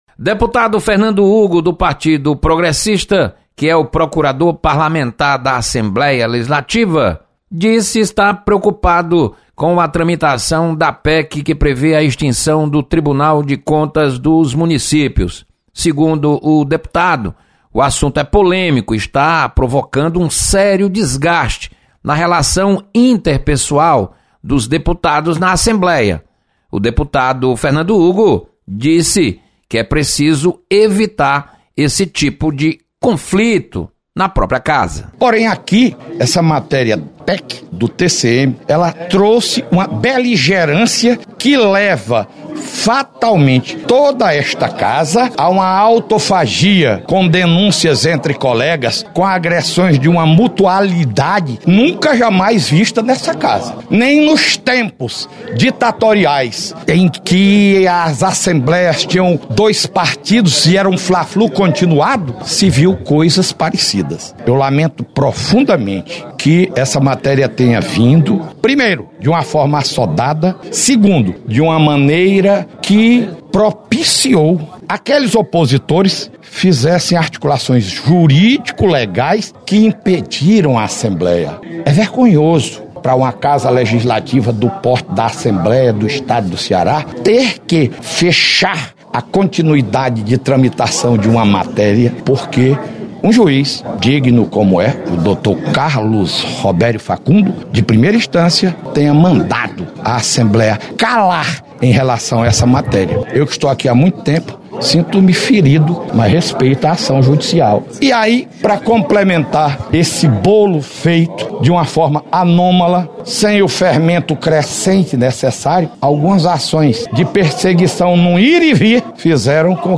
Deputados Fernando Hugo e Ely Aguiar comentam sobre debates em torno de extinção do TCM.